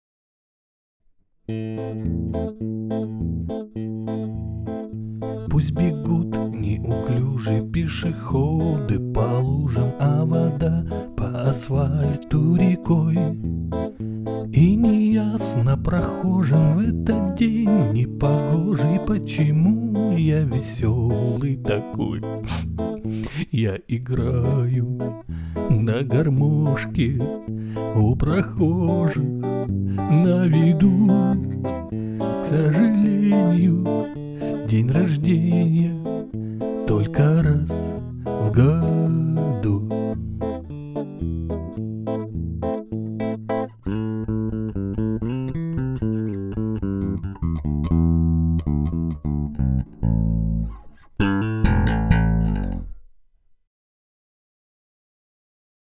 Tascam US-122 mkII record test 947,18 ��